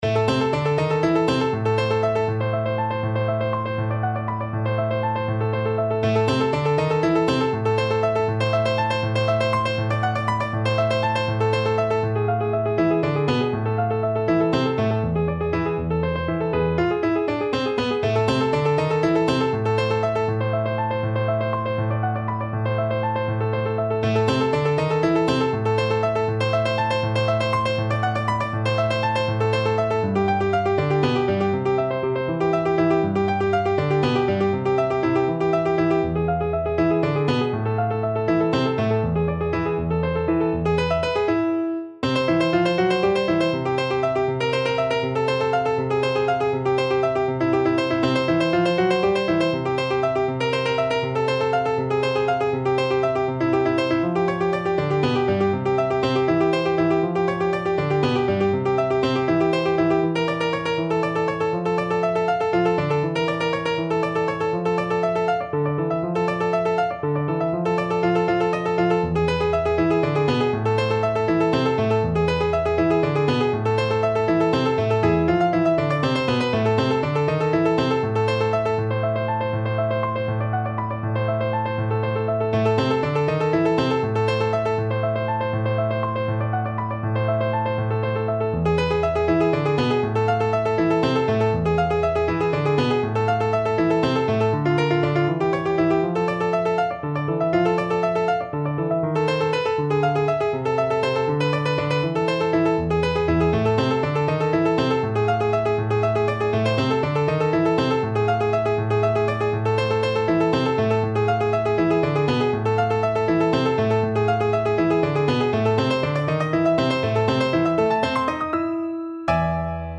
Free Sheet music for Piano
No parts available for this pieces as it is for solo piano.
C major (Sounding Pitch) (View more C major Music for Piano )
6/8 (View more 6/8 Music)
Piano  (View more Advanced Piano Music)
Classical (View more Classical Piano Music)